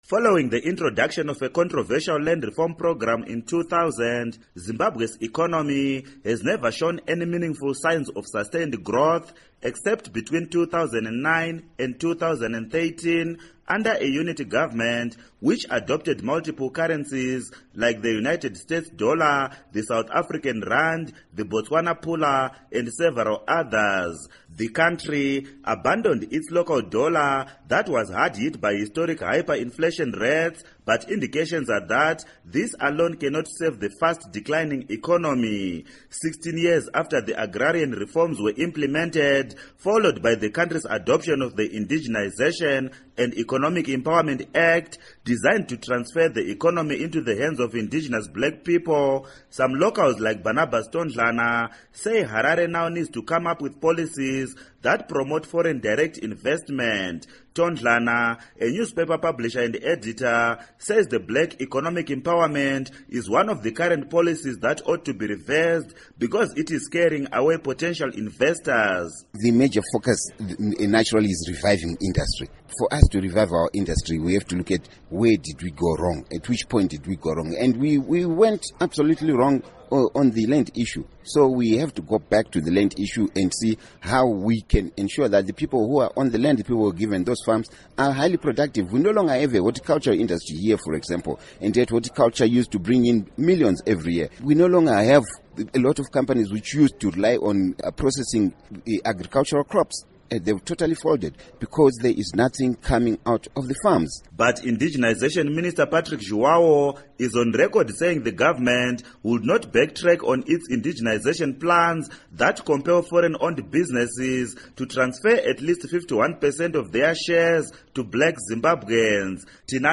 Report On Economy